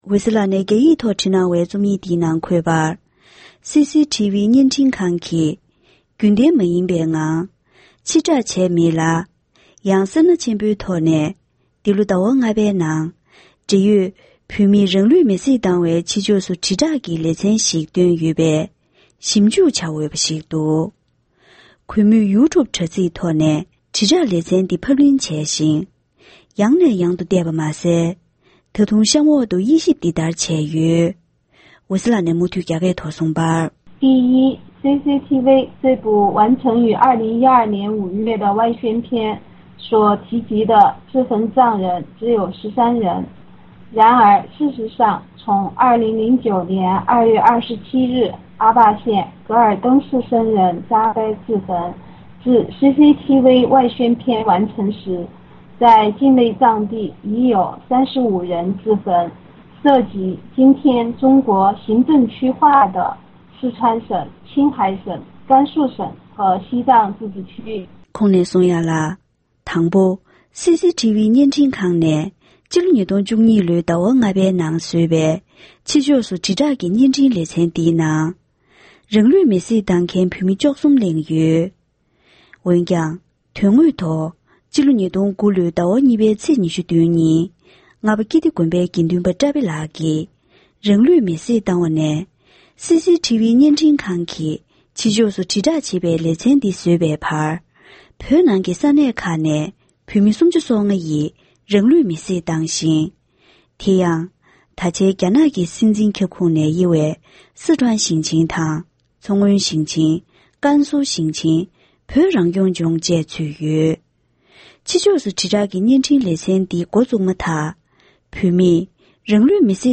ཕབ་བསྒྱུར་དང་སྙན་སྒྲོན་ཞུས་པར་གསན་རོགས་ཞུ༎